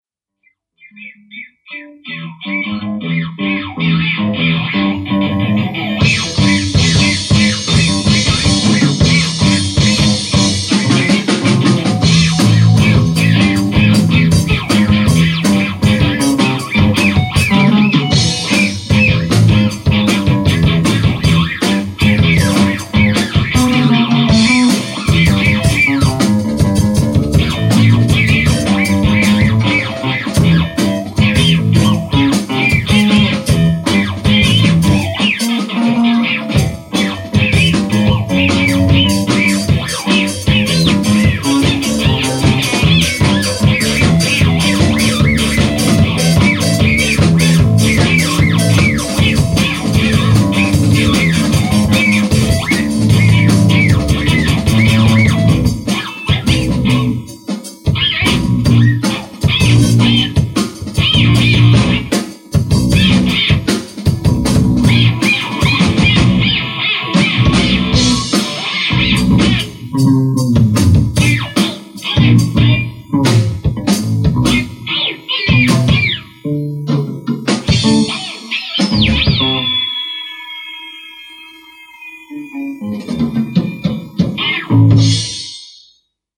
live jam - September 2005